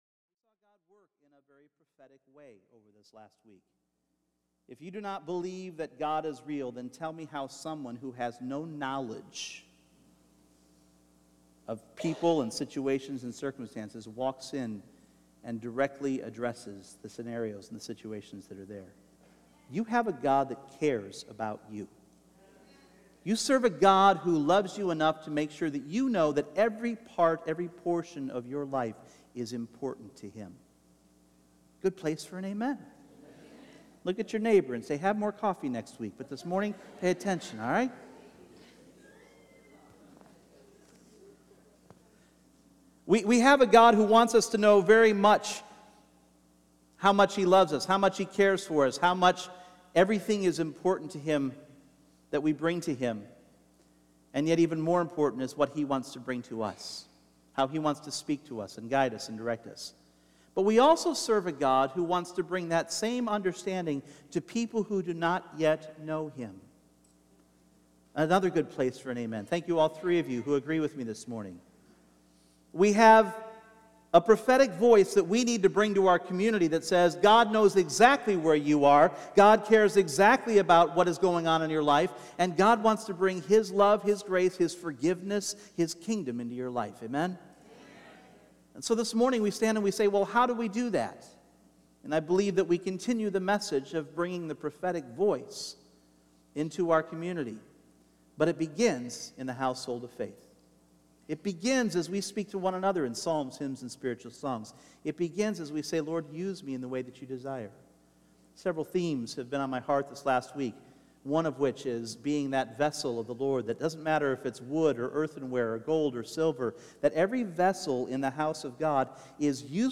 Three conditions and three purposes to our prophetic voice emerge from the prophets in Antioch. This is part two of the message preached two weeks prior.
Individual Messages Service Type: Sunday Morning Three conditions and three purposes to our prophetic voice emerge from the prophets in Antioch.